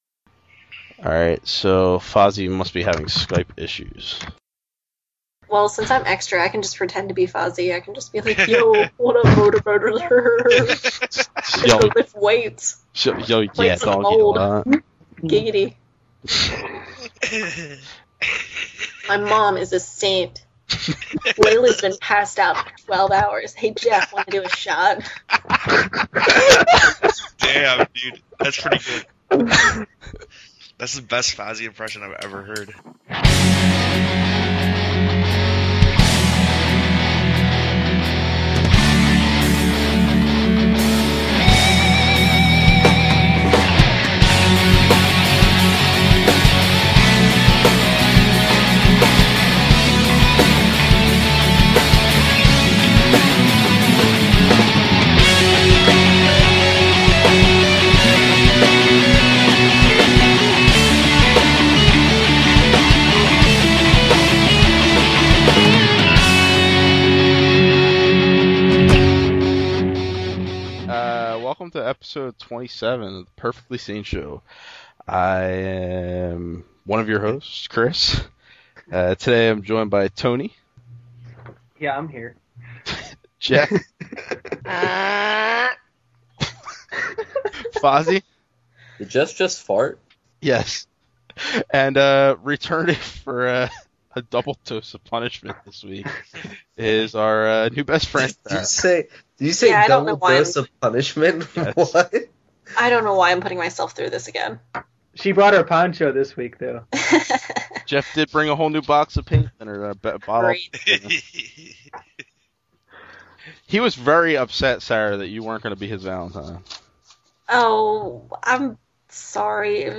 This episode, like all our classic episodes, appears unedited except for some music we don’t have (and never had) the license too (and Spotify makes us edit out now).